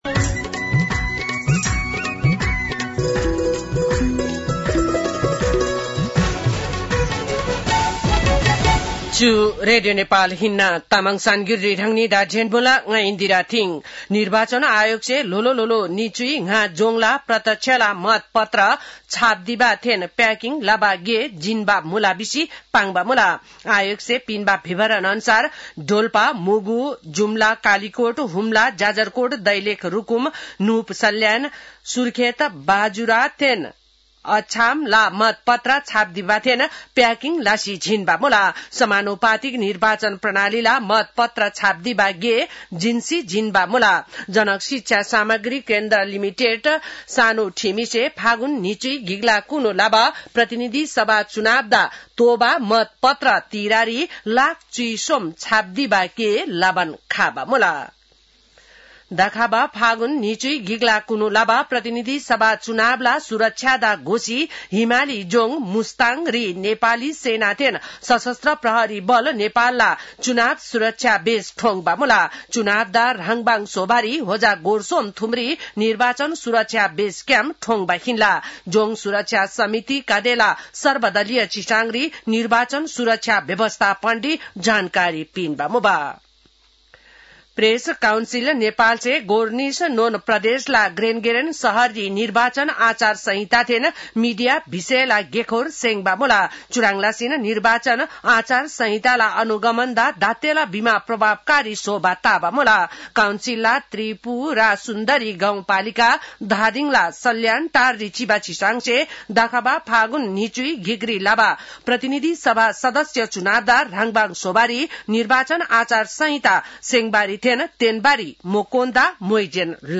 तामाङ भाषाको समाचार : २३ माघ , २०८२